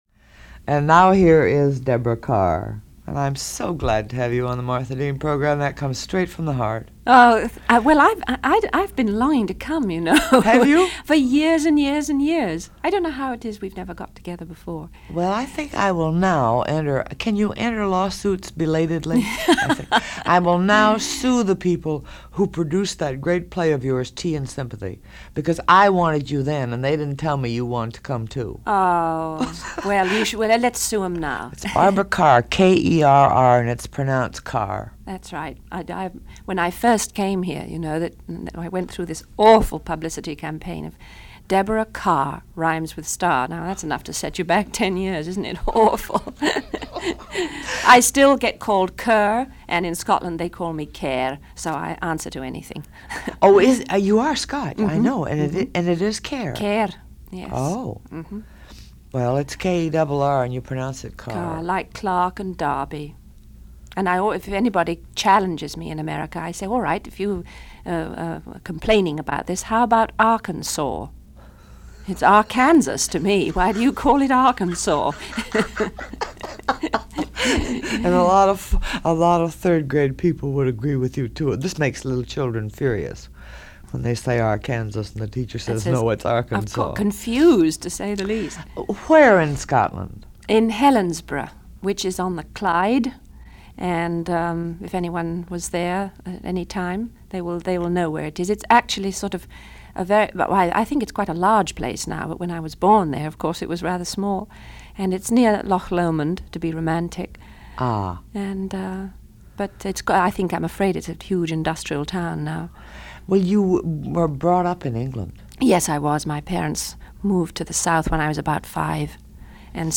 This interview, from The Martha Deane Program on WOR in New York, from February 25, 1969, coincides with her finishing work on an upcoming film, The Arrangement which was to be released later in the year. Although it’s not a promotion for the film (which was still being edited), she talks about working on it as well as giving a series of anecdotes on films throughout her career.